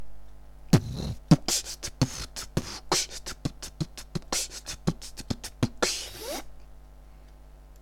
Brr b kch tt b t b kch tt b t b t b kch tt b tt b t b kch вууть
вторая версия немного помедленнее, записал на всякий случай